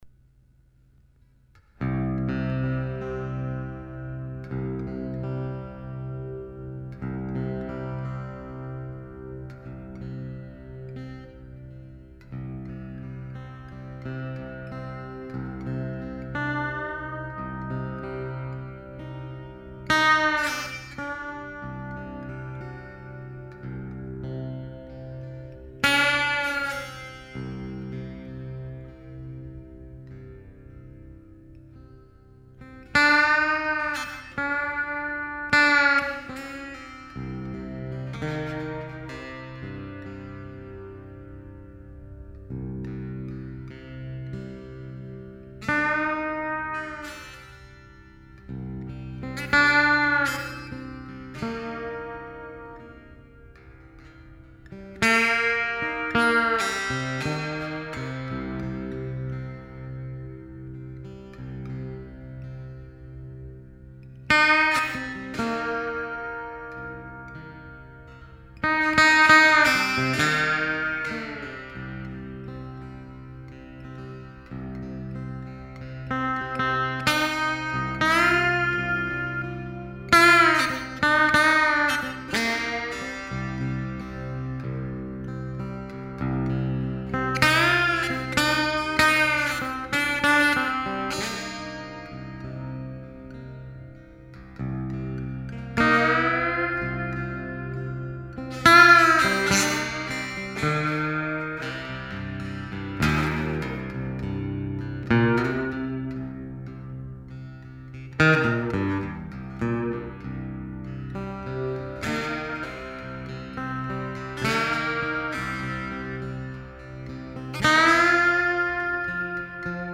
une longue improvisation guitare au bottleneck